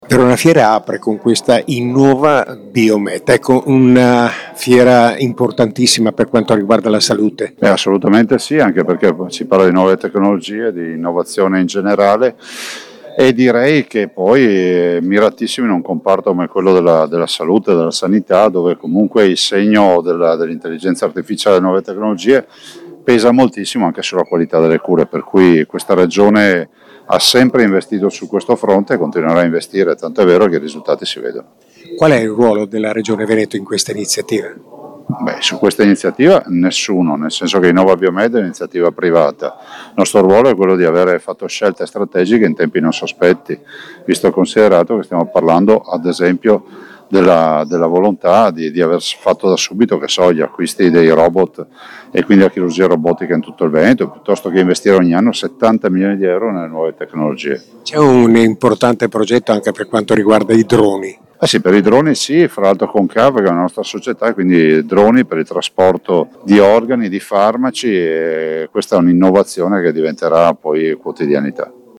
Presente all’inaugurazione anche il presidente della Regione Veneto Luca Zaia, intervistato dal nostro corrispondente